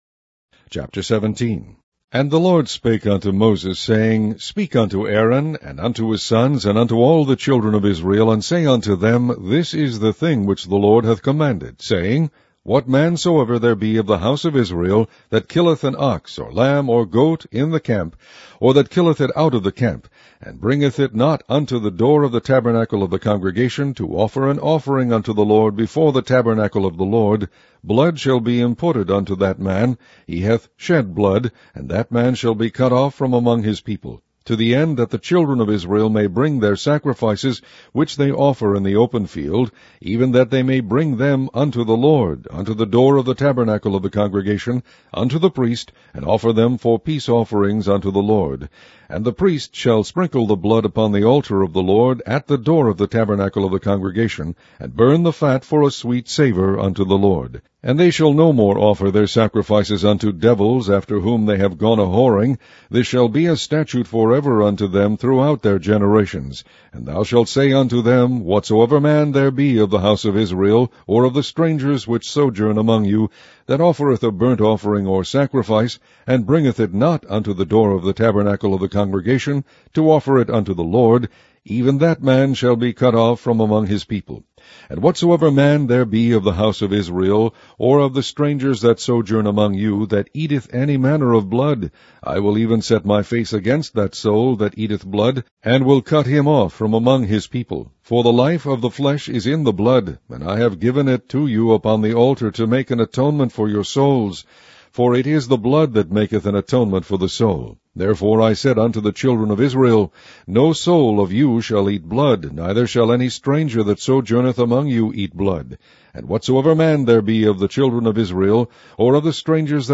Online Audio Bible - King James Version - Leviticus